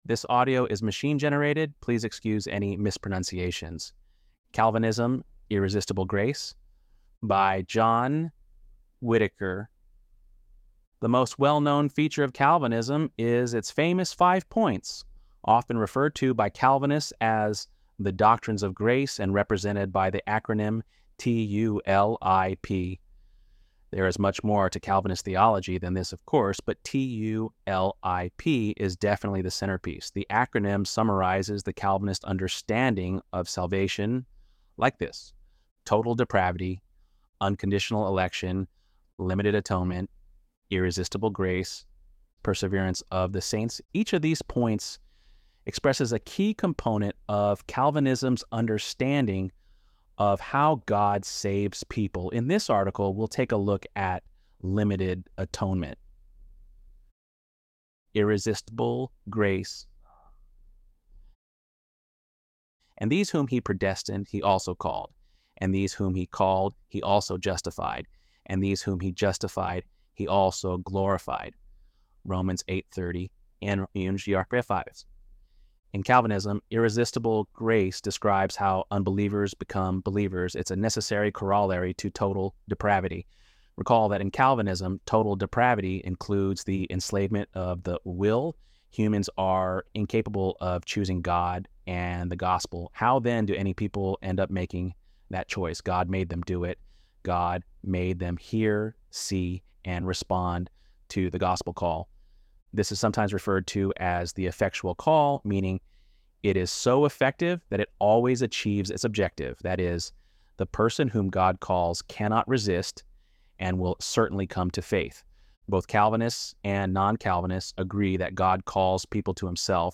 ElevenLabs_7.11_TULIP_Pt_4.mp3